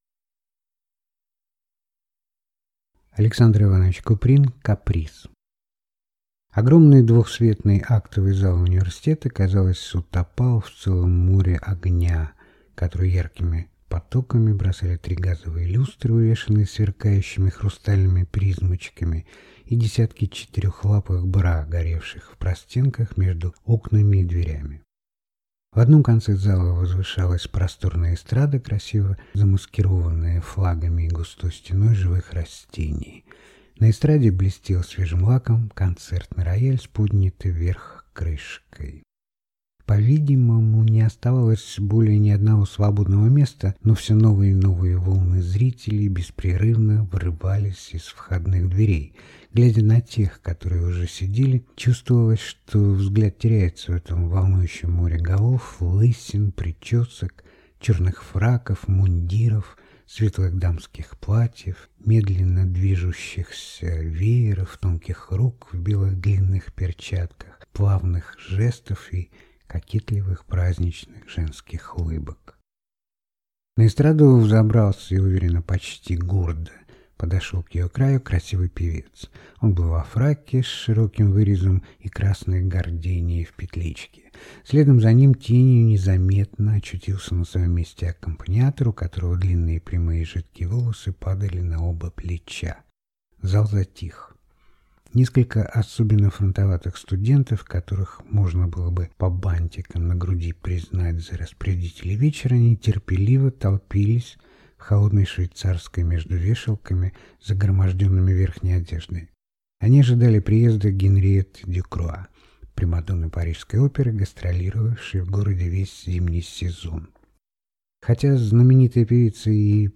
Аудиокнига Каприз | Библиотека аудиокниг